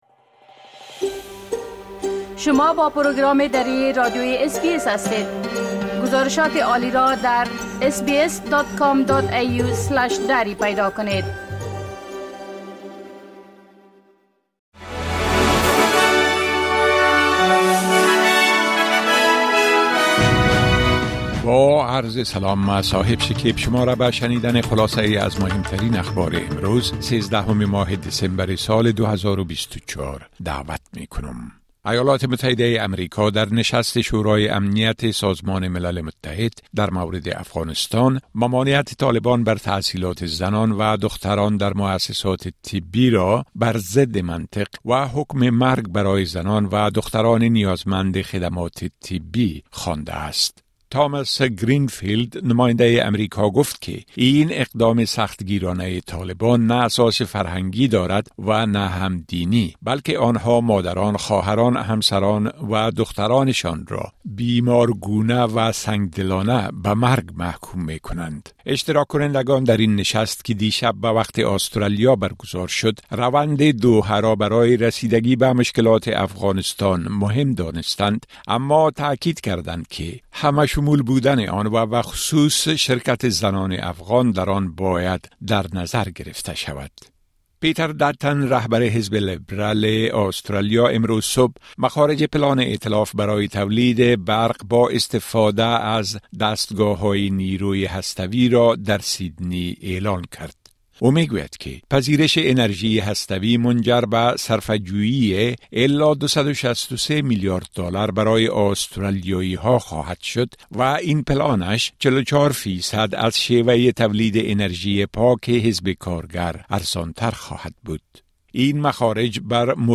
خلاصۀ اخبار مهم امروز ۱۳ دسمبر ۲۰۲۴ به زبان درى از اس بى اس را در اينجا شنيده مى توانيد.